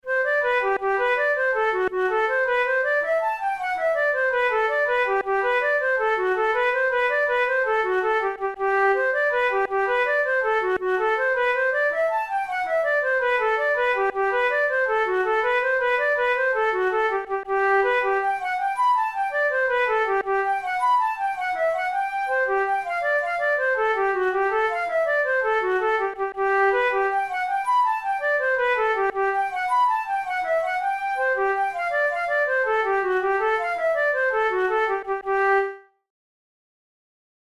InstrumentationFlute solo
KeyG major
Time signature6/8
Tempo108 BPM
Traditional Irish jig